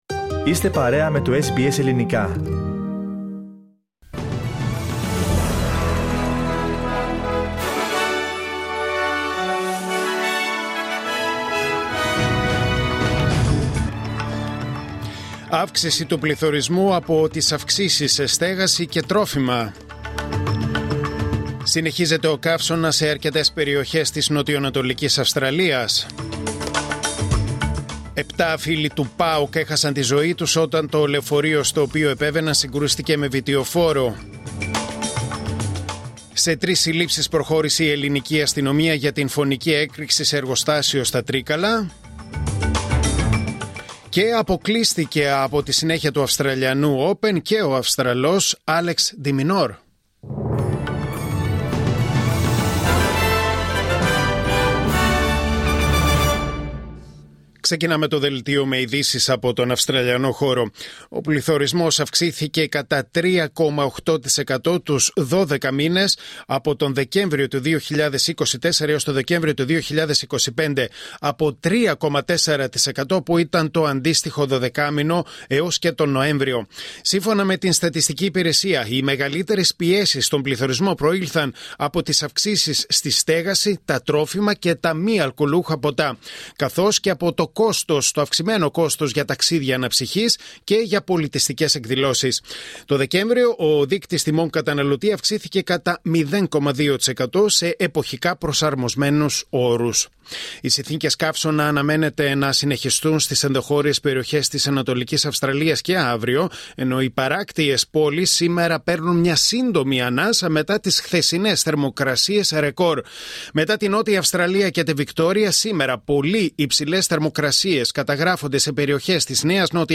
Δελτίο Ειδήσεων Τετάρτη 28 Ιανουαρίου 2026